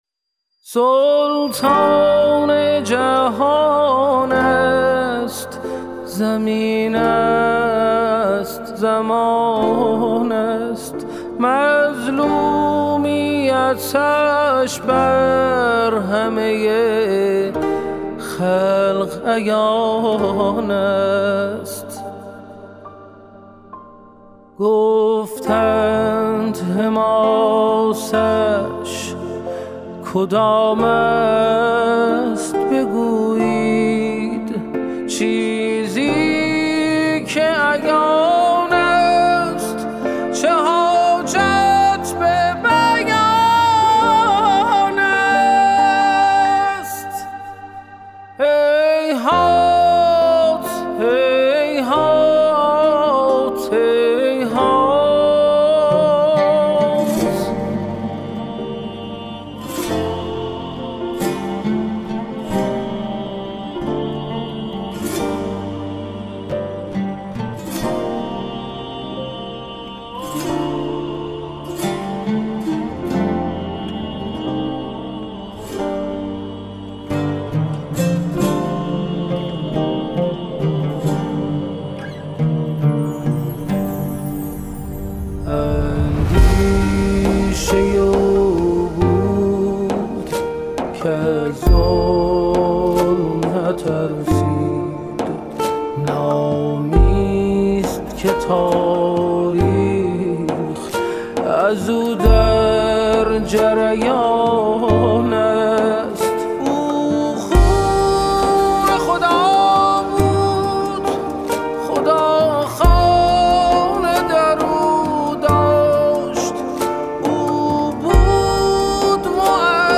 تیتراژ برنامه